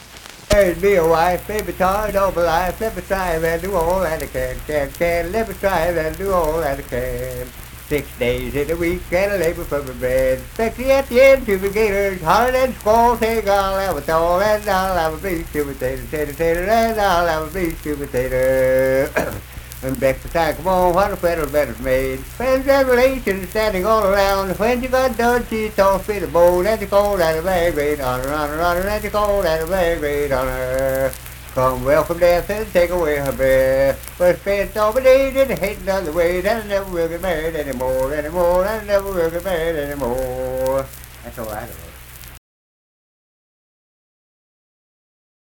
Unaccompanied vocal and banjo music
Voice (sung)